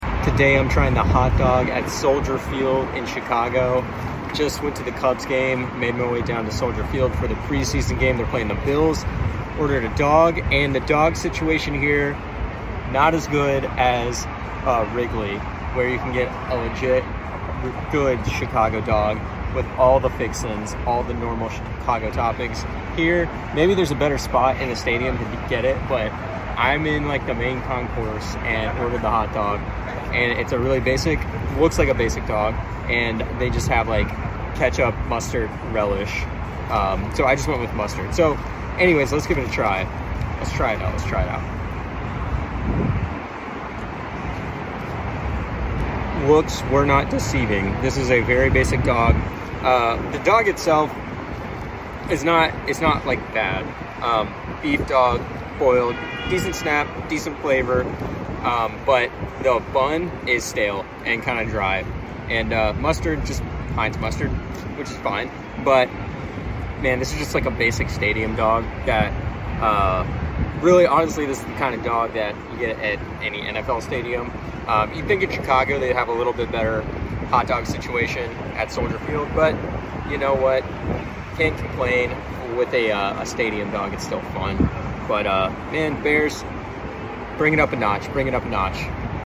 Hot dog at the Chicago Bears game at Soldier Field in Chicago, Illinois